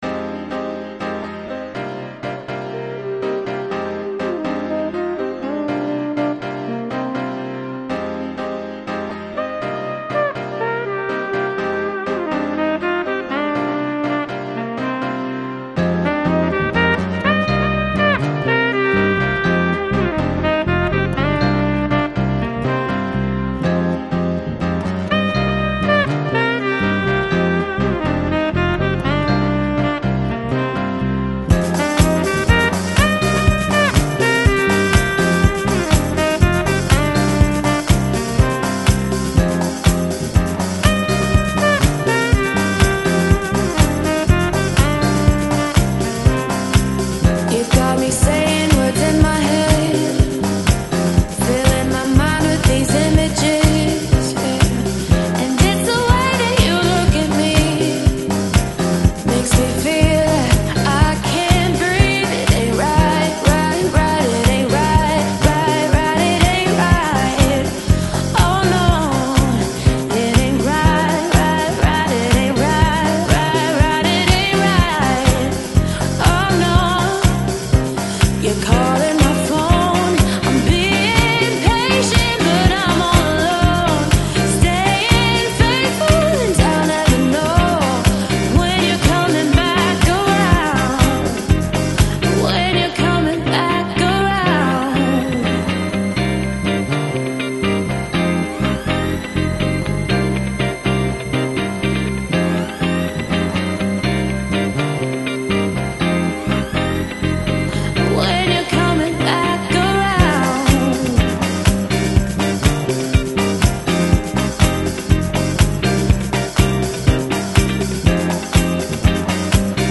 Soulful House, Deep House, Chill House